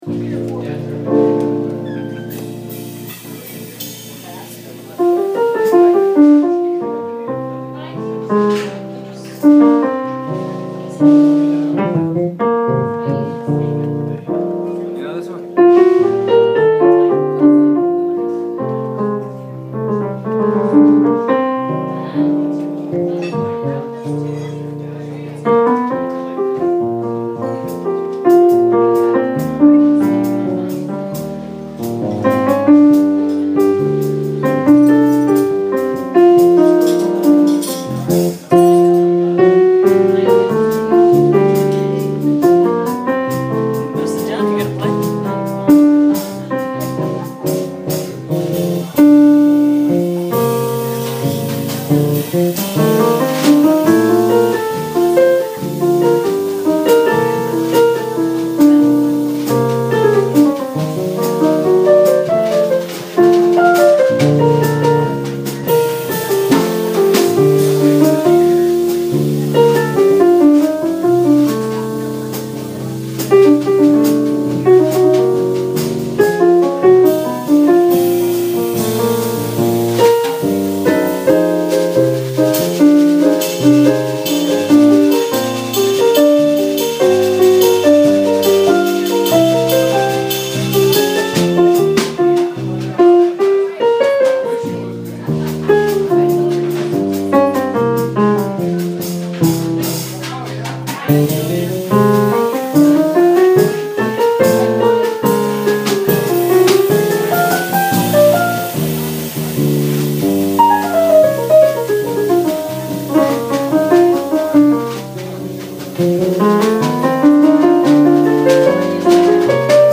Here are some clips from recent jazz performances:
Recorded Live at Harvey's Steakhouse
drums